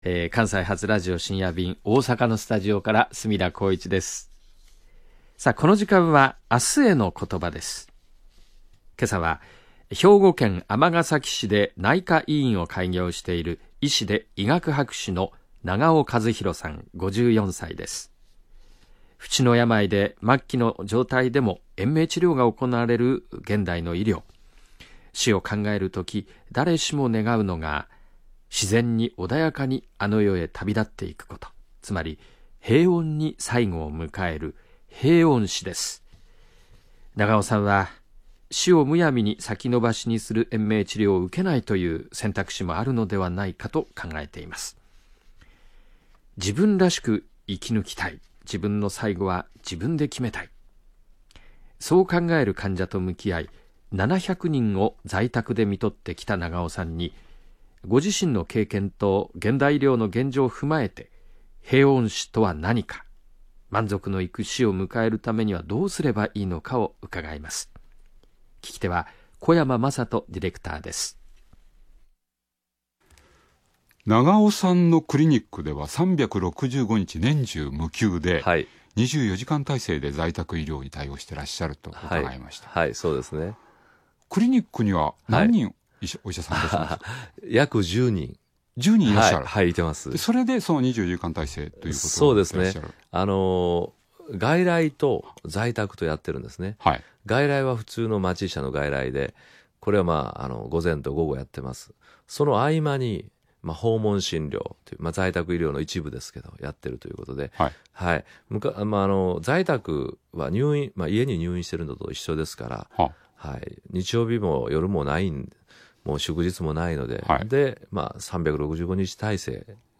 ついでに、少し前ですが、 NHKでラジオでのトーク です。
radio_shinya.mp3